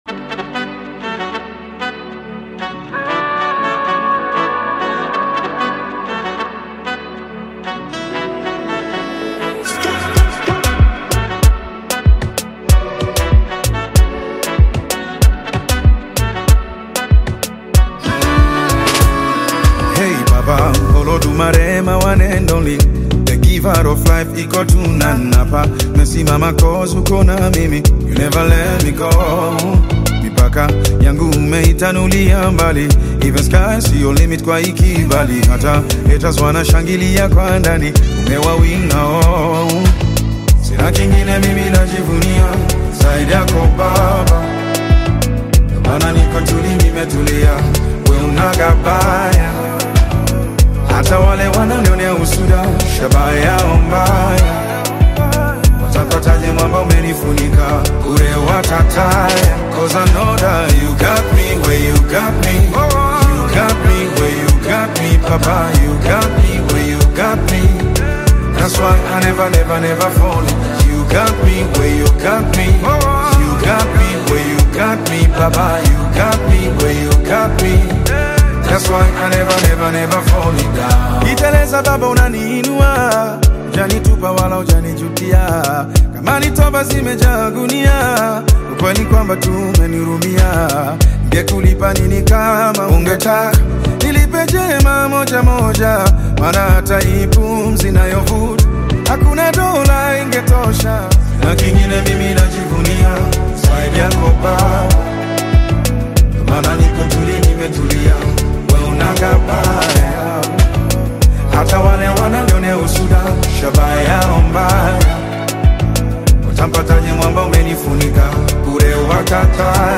a talented Tanzanian gospel minister
Gospel Songs Mp3 Free Download...